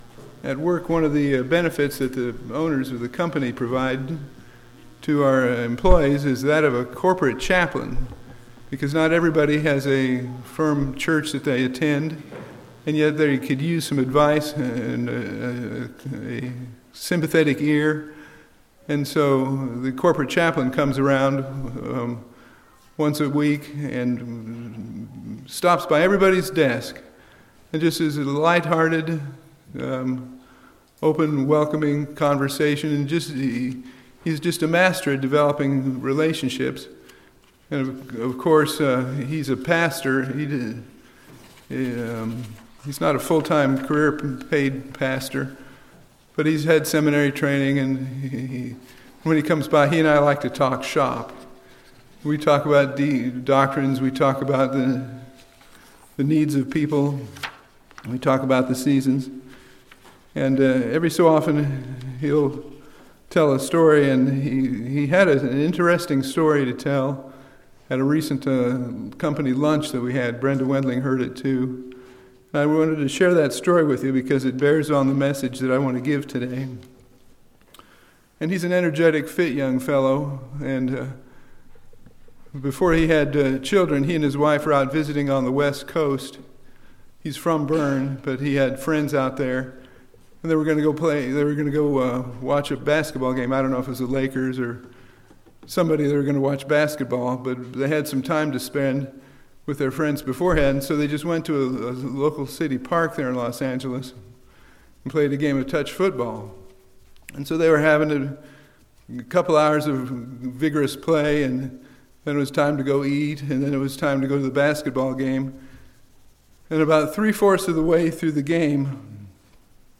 This sermon explores the topic of the lost being found and all the rejoicing that goes with it. How does God rejoice and how should we rejoice?